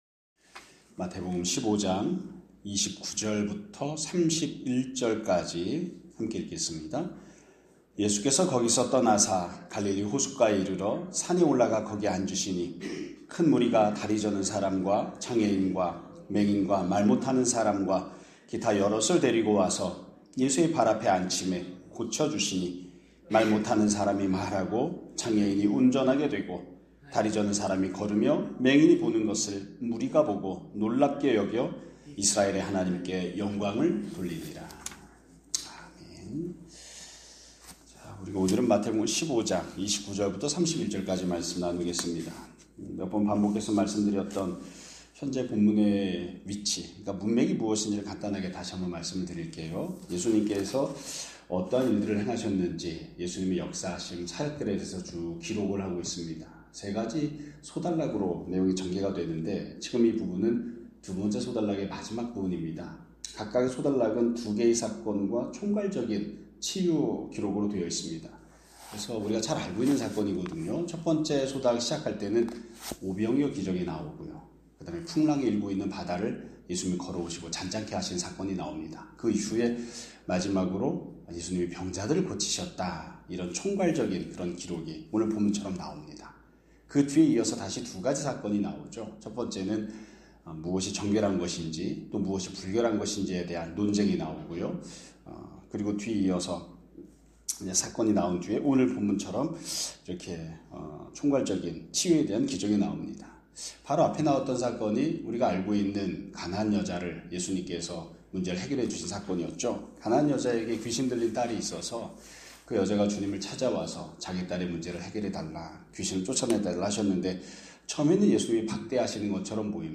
2025년 11월 10일 (월요일) <아침예배> 설교입니다.